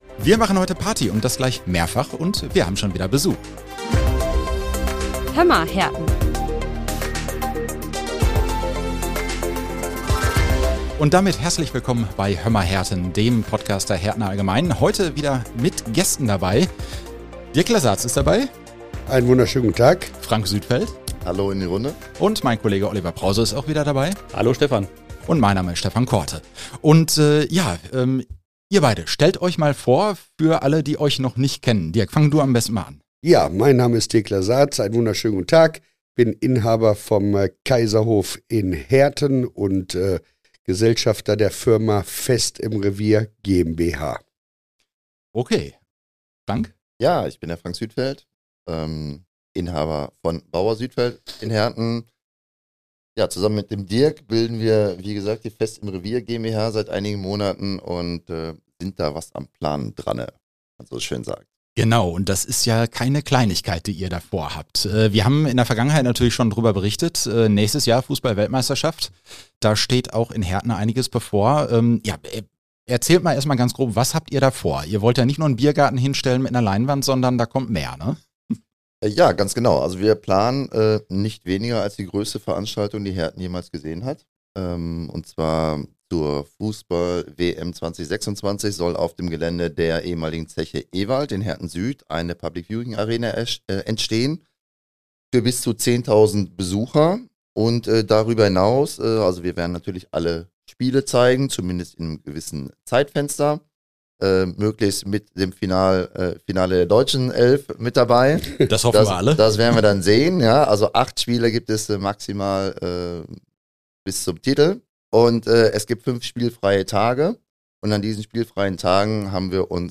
Gäste